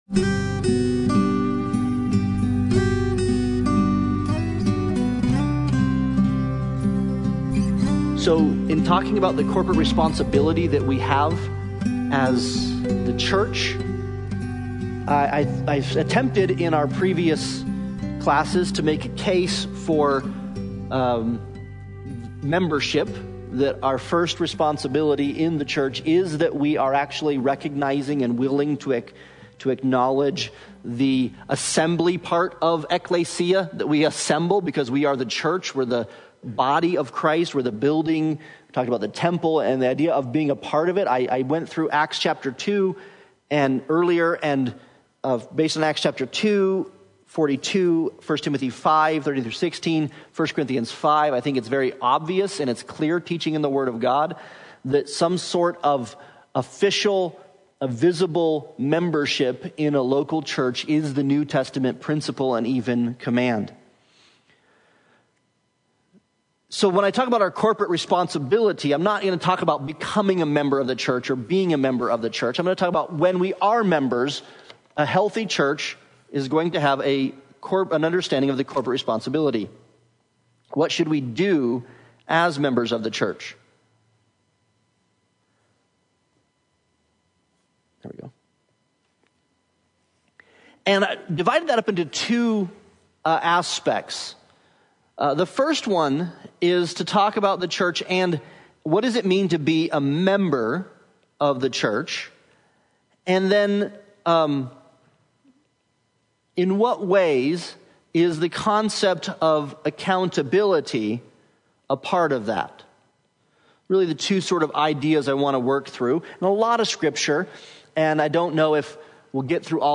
Service Type: Sunday Bible Study